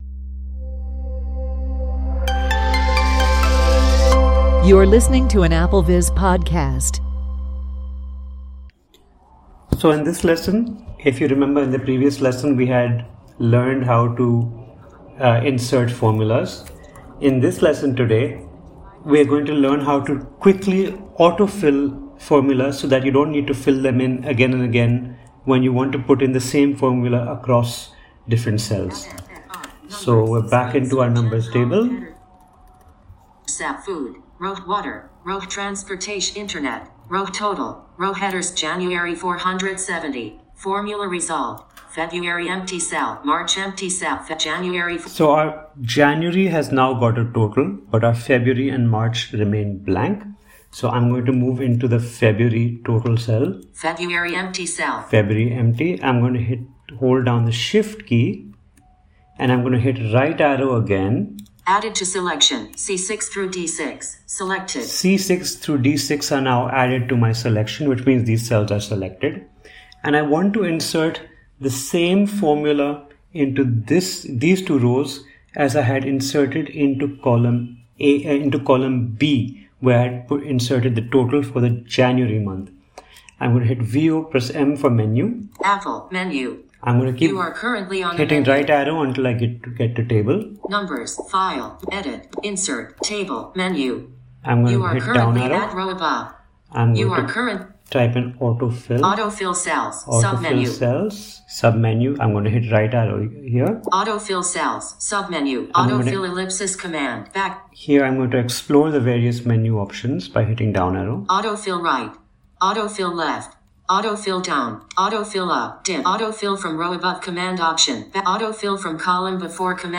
Walk-through